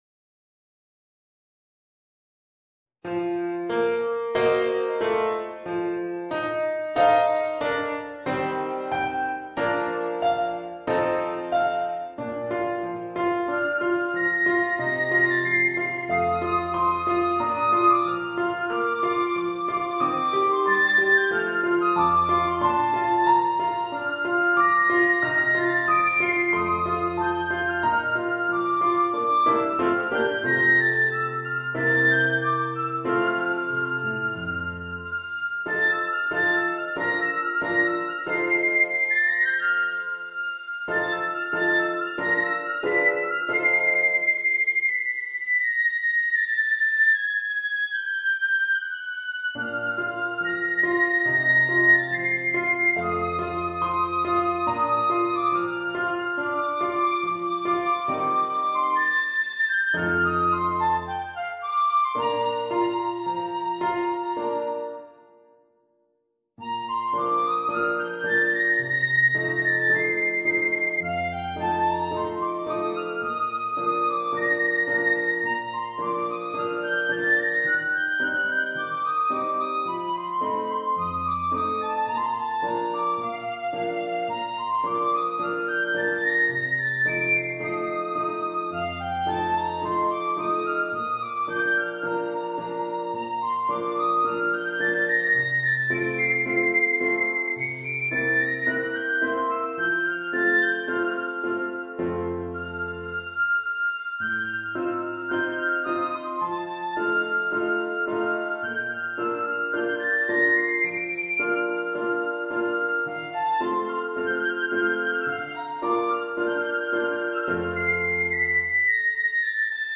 Voicing: Piccolo and Piano